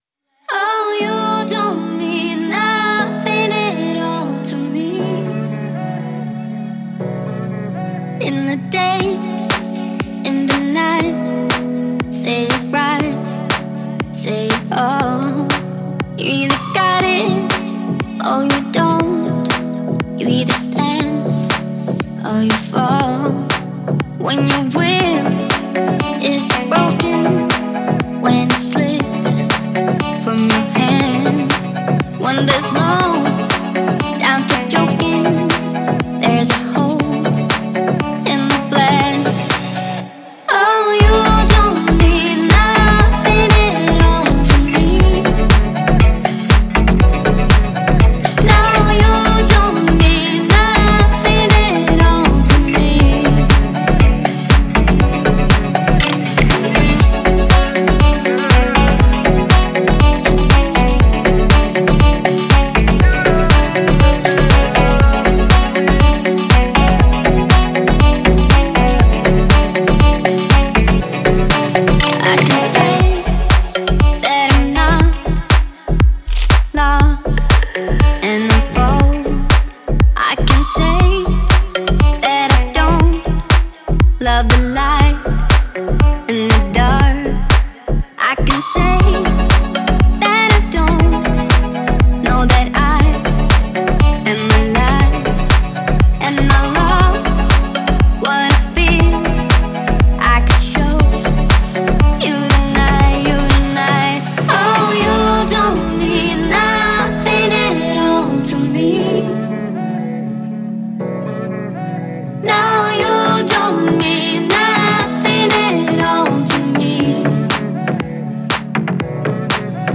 это динамичная композиция в жанре EDM